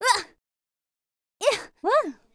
fishing_catch_v.wav